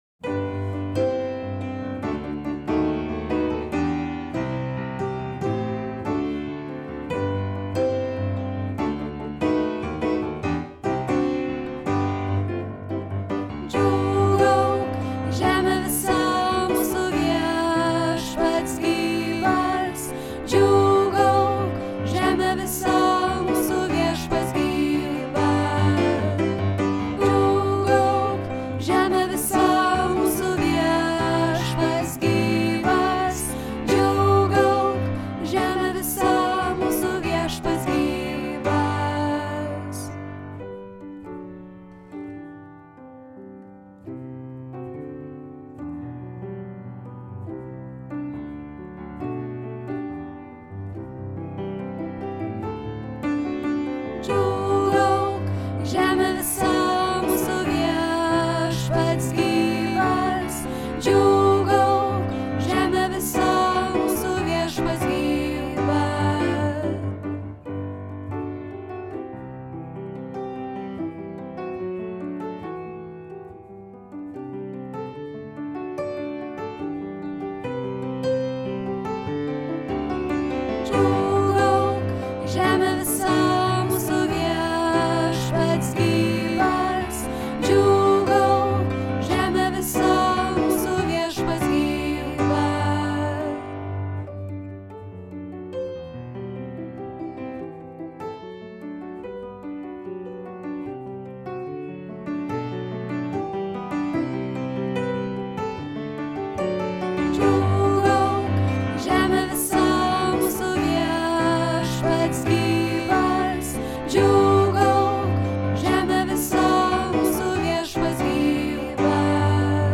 SOPRANAS